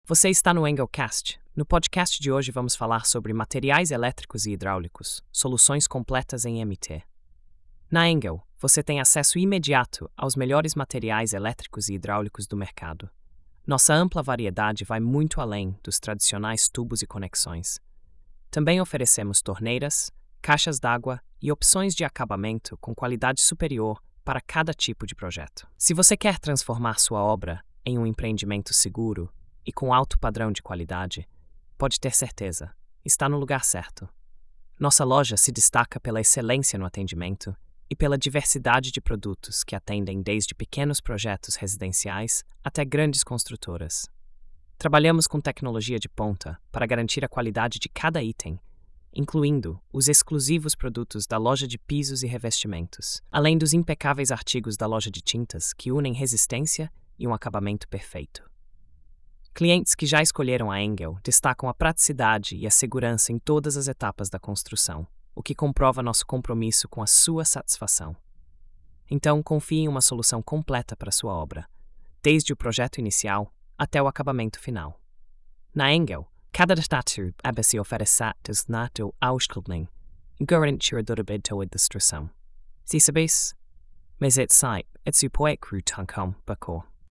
Narração automática por IA • Construção & Reformas